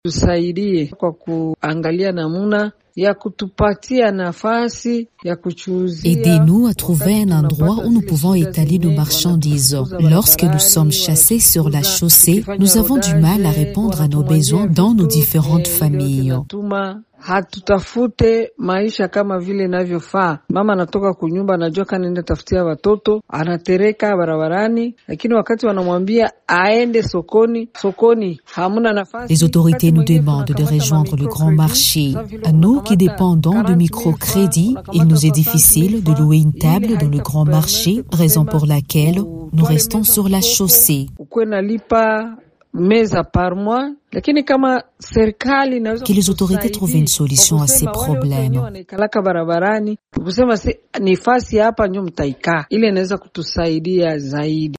L’une d’elles en parle.